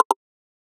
switch_002.ogg